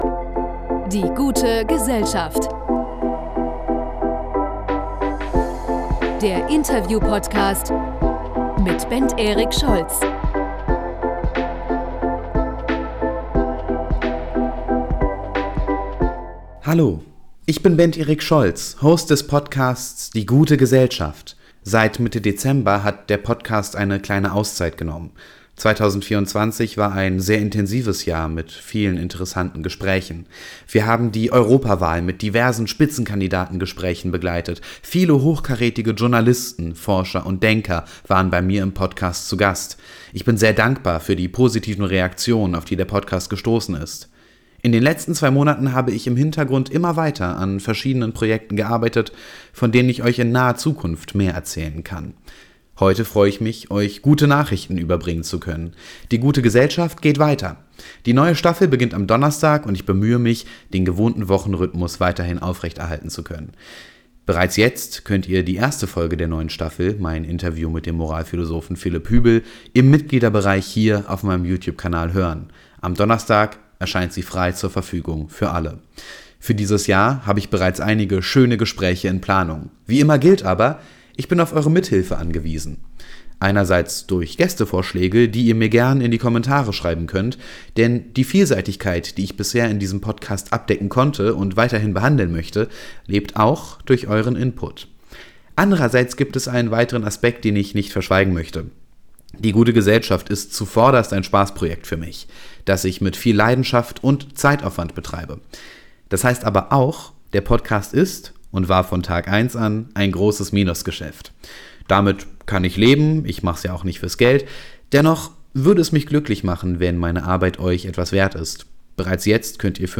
DGG_Teaser.mp3